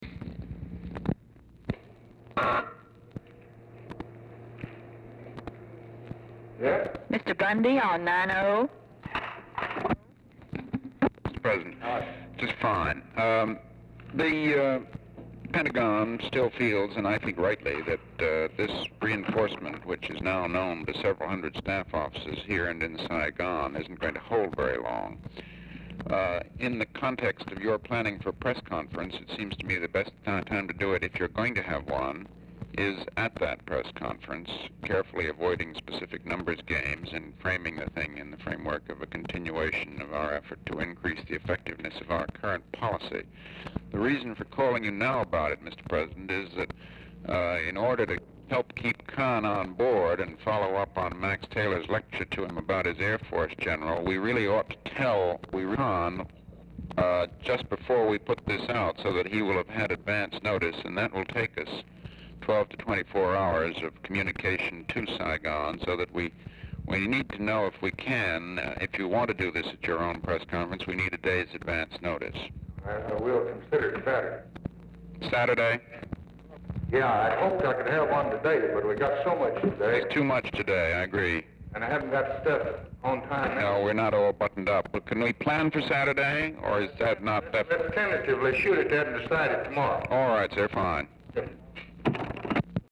Telephone conversation # 4312, sound recording, LBJ and MCGEORGE BUNDY, 7/23/1964, 10:13AM | Discover LBJ
Format Dictation belt
Location Of Speaker 1 Oval Office or unknown location
Specific Item Type Telephone conversation Subject Defense Diplomacy Press Relations Vietnam